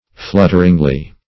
flutteringly - definition of flutteringly - synonyms, pronunciation, spelling from Free Dictionary Search Result for " flutteringly" : The Collaborative International Dictionary of English v.0.48: Flutteringly \Flut"ter*ing*ly\, adv. In a fluttering manner.
flutteringly.mp3